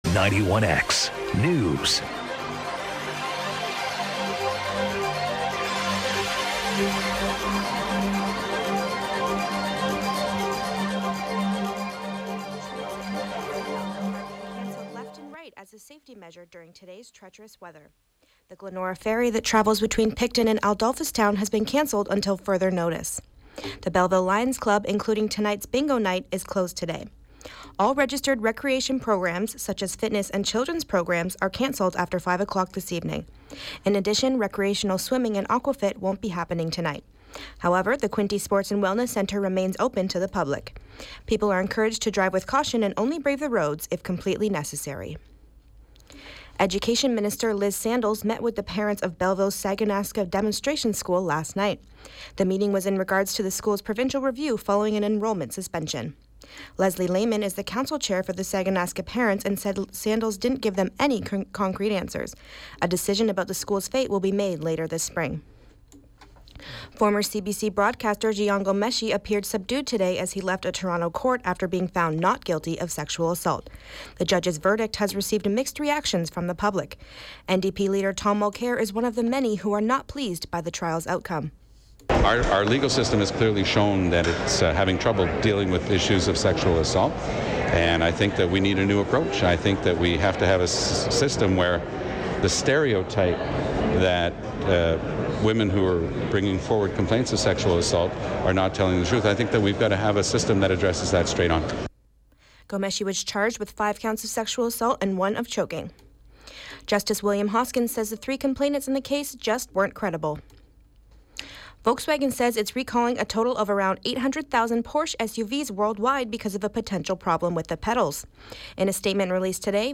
91X Newscast – Thursday, March 24, 2016 – 3 p.m.
March-24-3p.m.-newscast.mp3